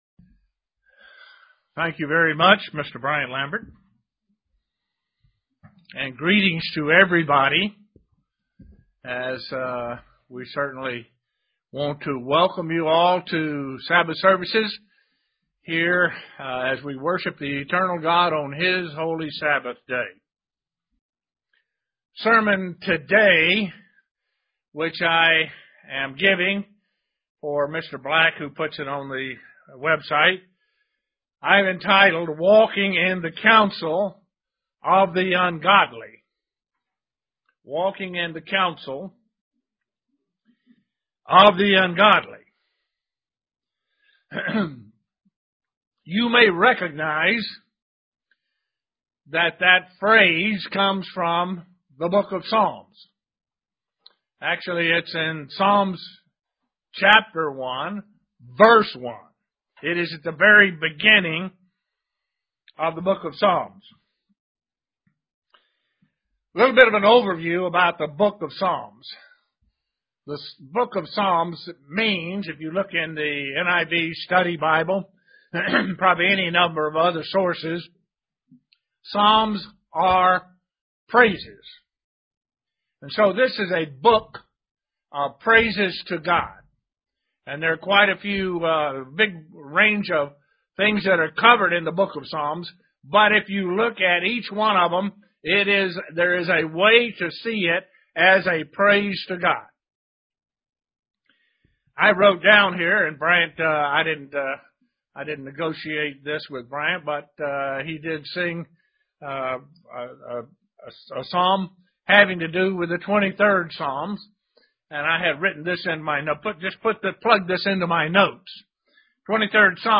Given in Elmira, NY
UCG Sermon Studying the bible?